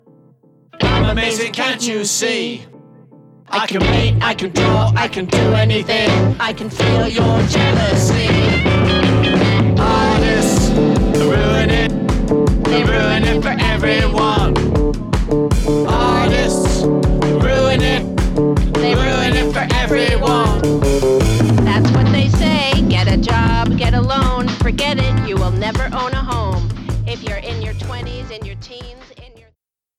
a fast-spun punky skank
The A-side is a noisy demonstration of rebellion
joint lead vocals